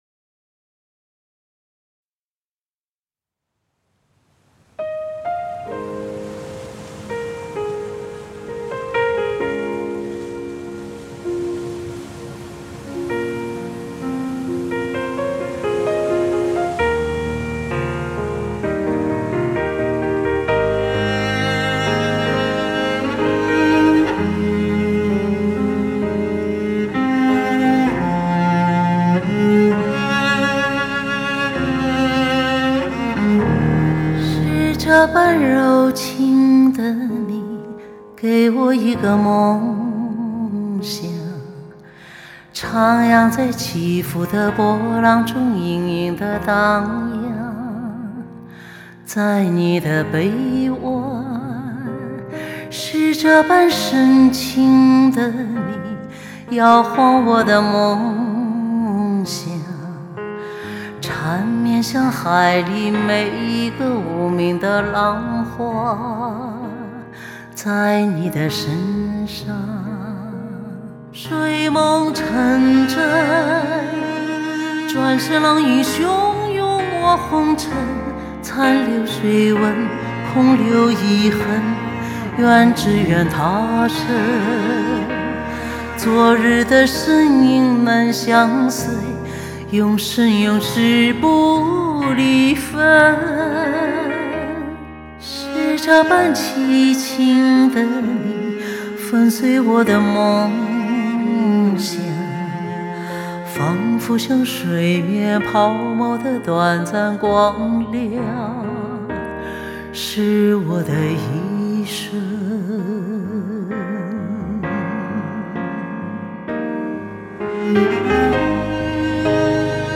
与同一母版的普通CD相比，LECD在动态、空间、结像、定位、细节和音场深阔与层次等音响效果都有更优良表现。